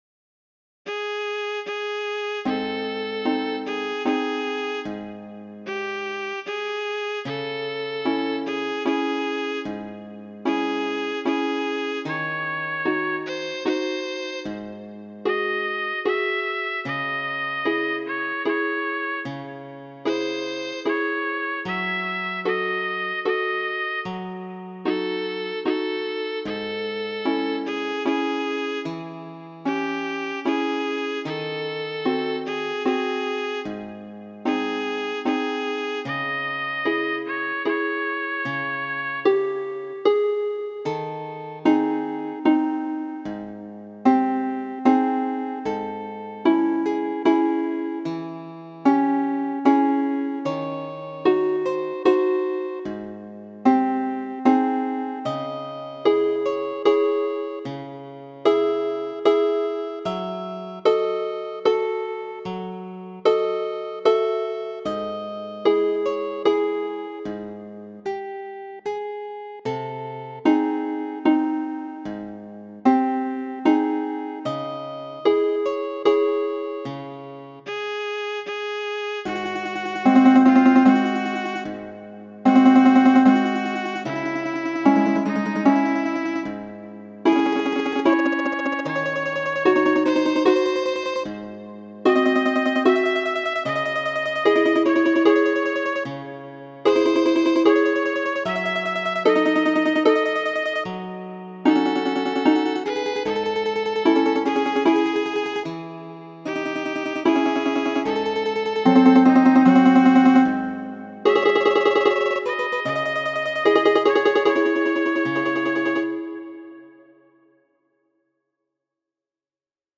Muziko:
Nigraj okuloj, fama rusa popola kanto malgaja.